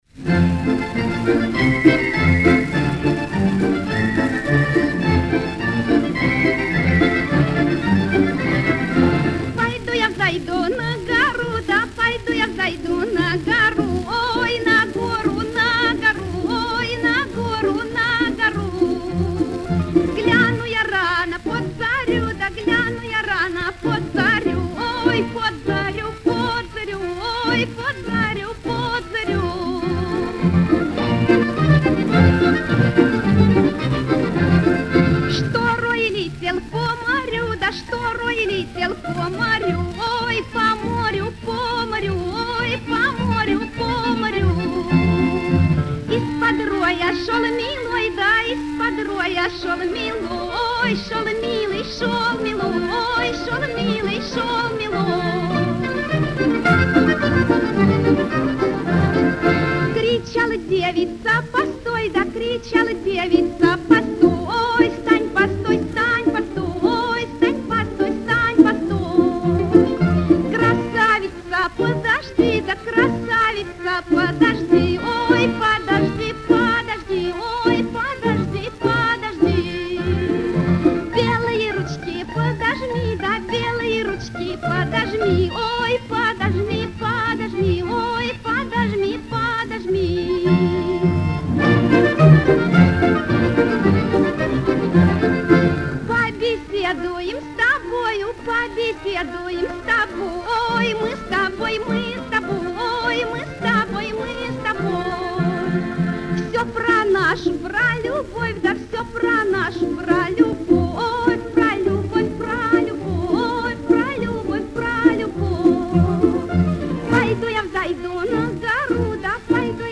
Музыка: народная Исполняет
Исполнение 1950г. Матрица 18061 (патефонная)